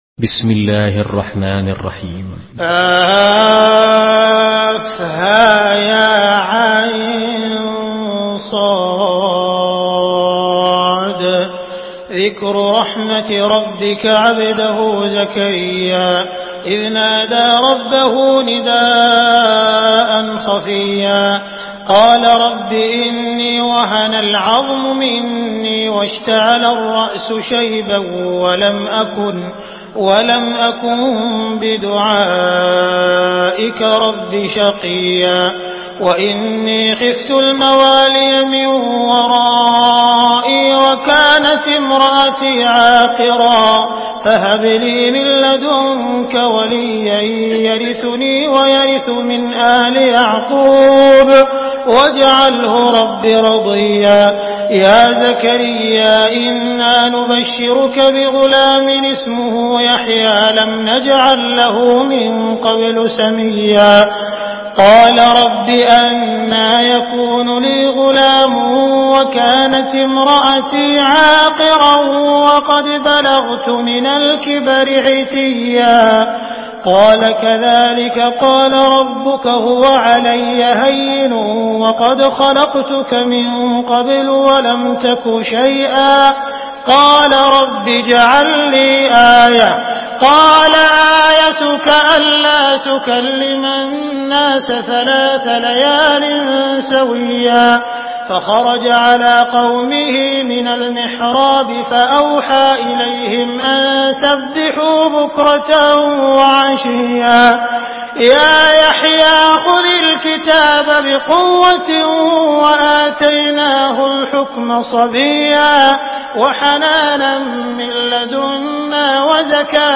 Surah মারইয়াম MP3 by Abdul Rahman Al Sudais in Hafs An Asim narration.
Murattal Hafs An Asim